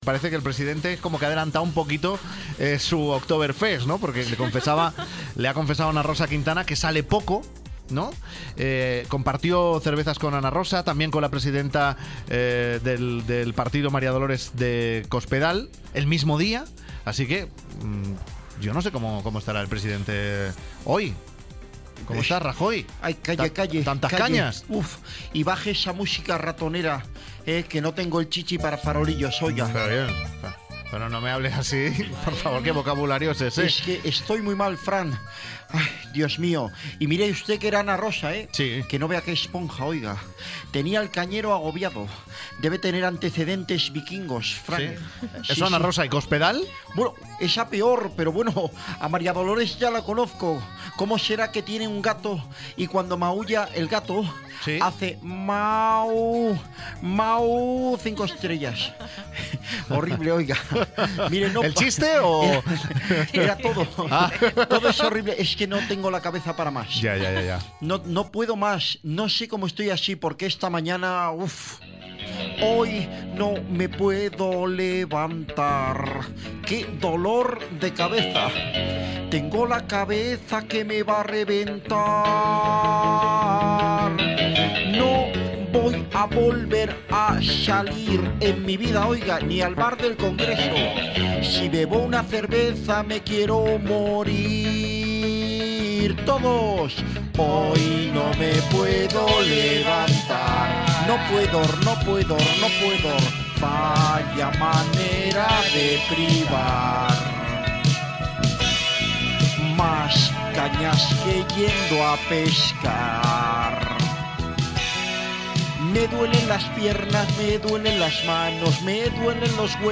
Frank Blanco habla con un falso Rajoy que le cuenta su estancia con Ana Rosa Quintana.